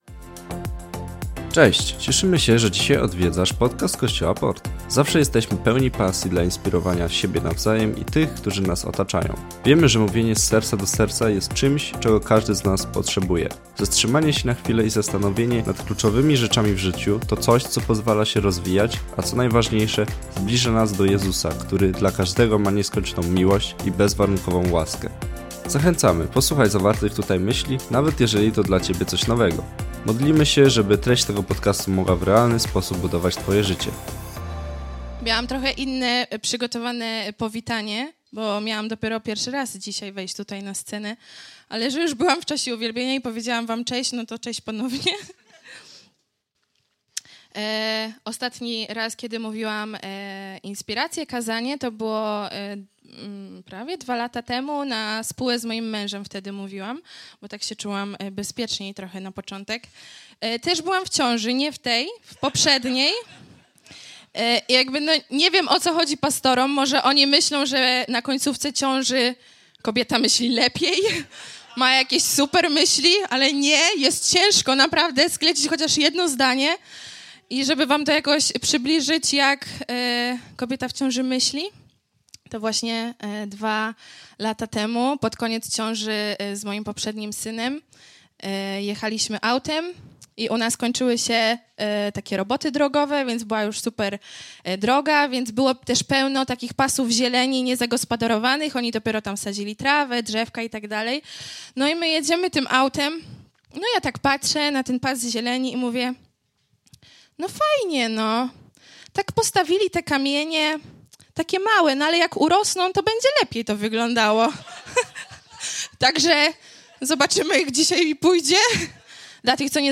kazania